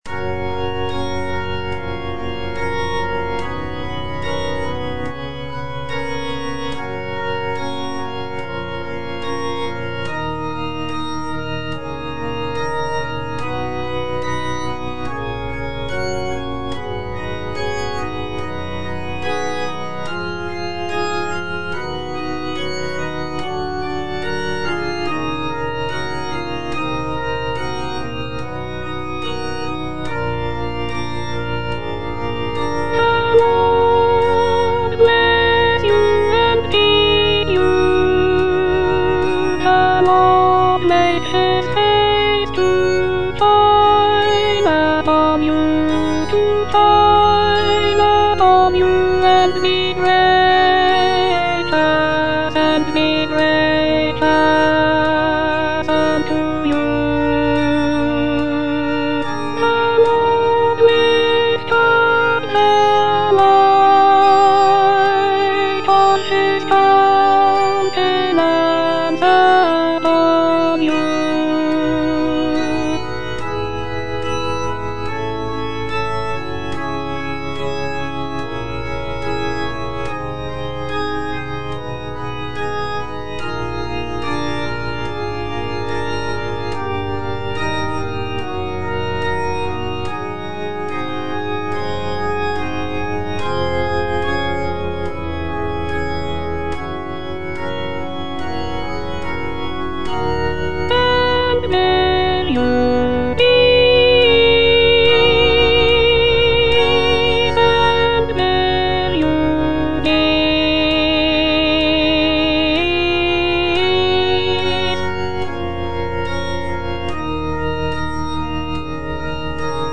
Alto (Voice with metronome)
choral benediction